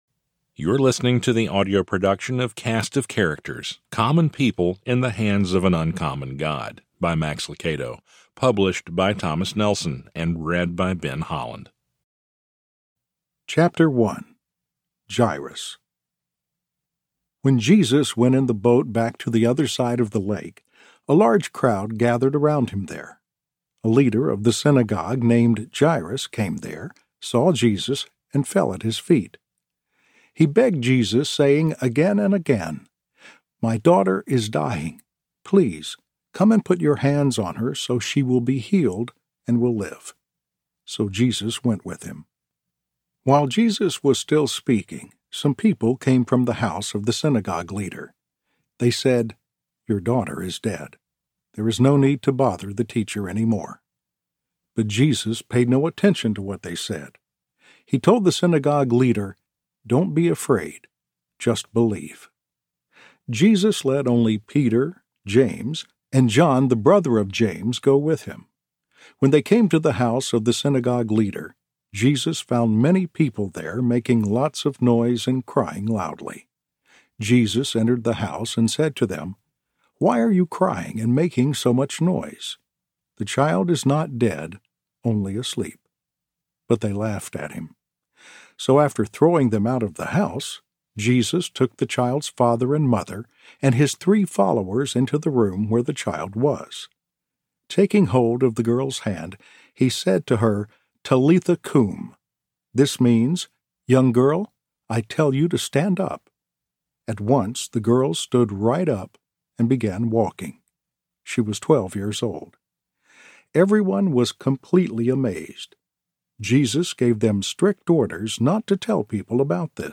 Narrator
6.0 Hrs. – Unabridged